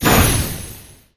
Magic_Poof02.wav